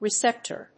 レセプター